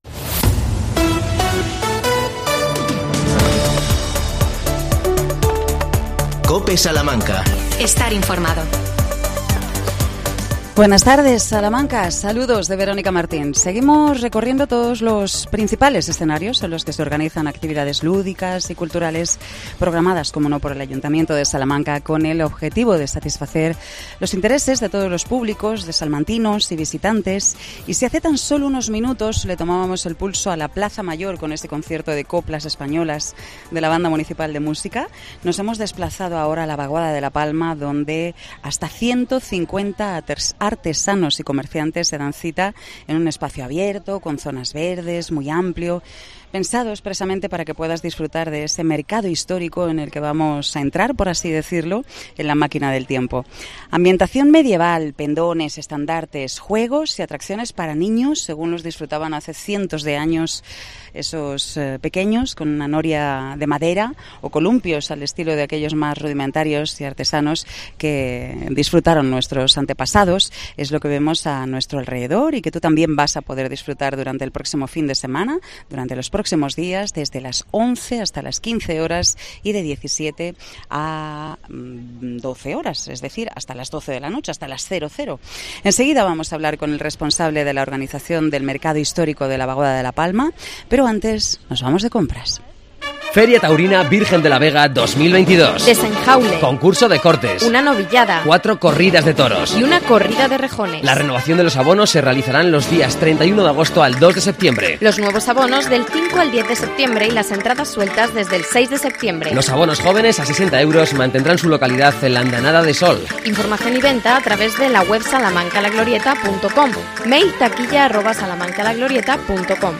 Desde el mercado histórico